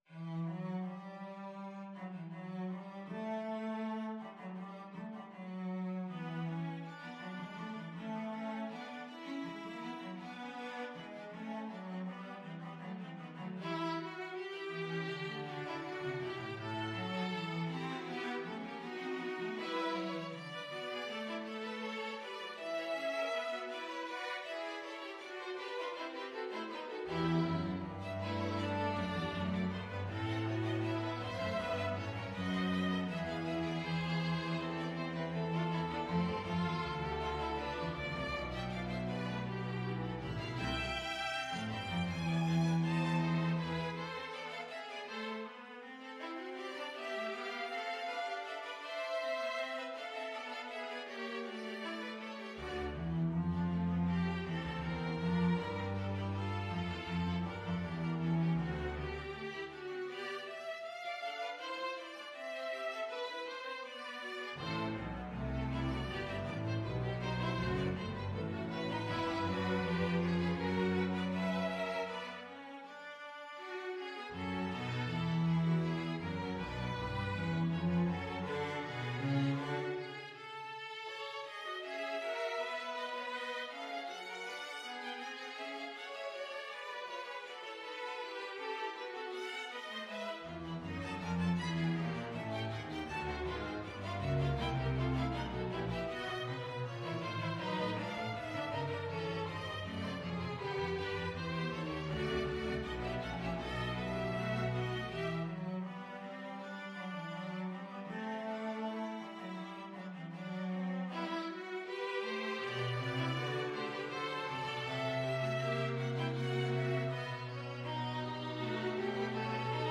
Free Sheet music for String Ensemble
Violin 1Violin 2ViolaCelloDouble Bass
G major (Sounding Pitch) (View more G major Music for String Ensemble )
2/2 (View more 2/2 Music)
Allegro =80 (View more music marked Allegro)
String Ensemble  (View more Intermediate String Ensemble Music)
Classical (View more Classical String Ensemble Music)